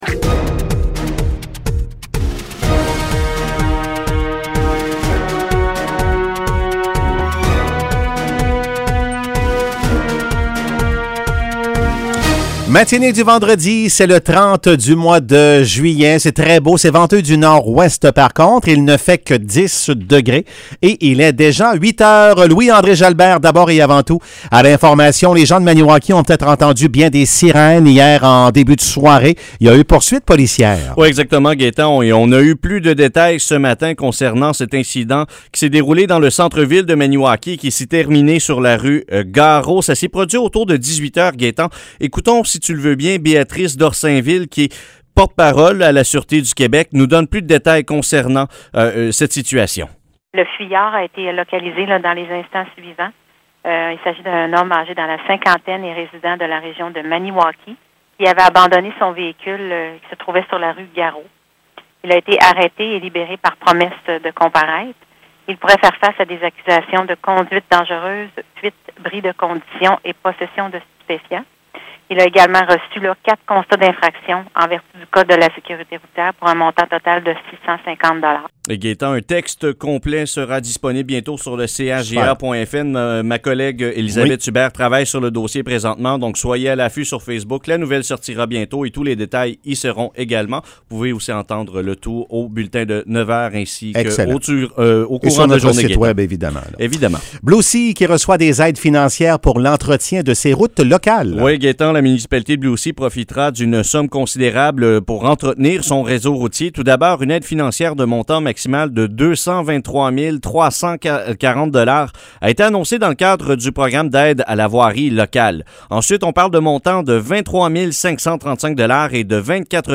Nouvelles locales - 30 juillet 2021 - 8 h